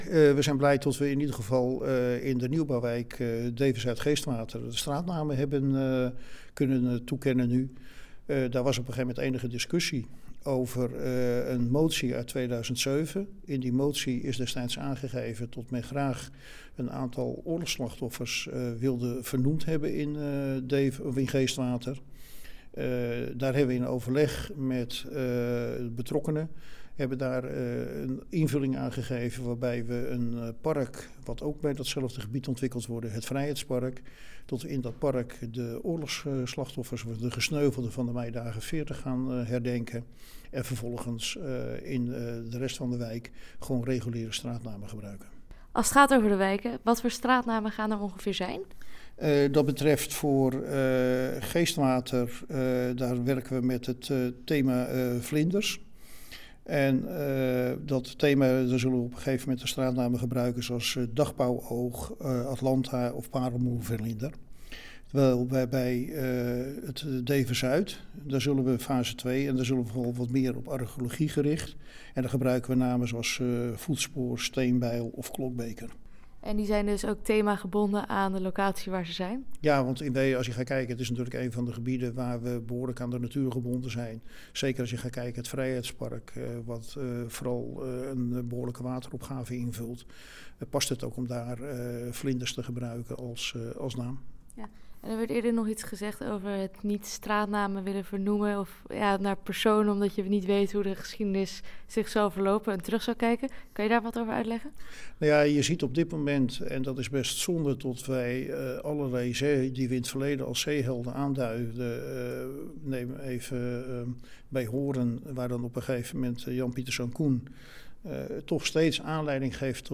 Interview met wethouder Kees van der Zwet